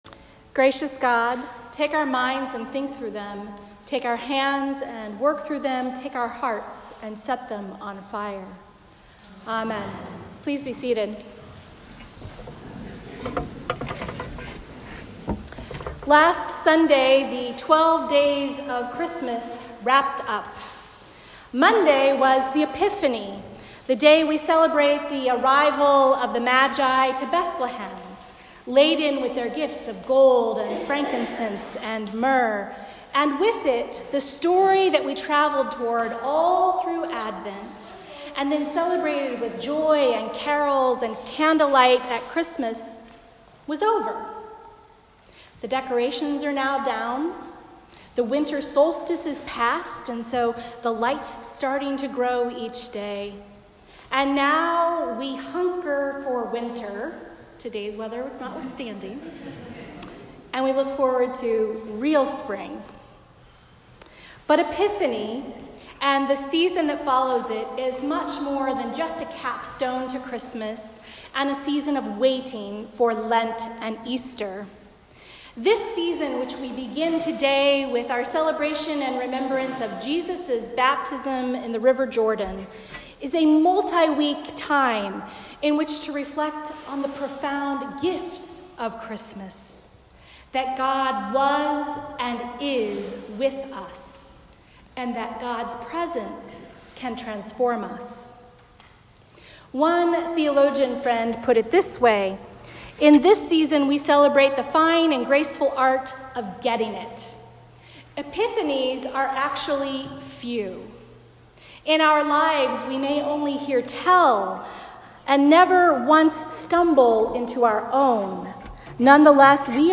A Sermon for the Baptism of Our Lord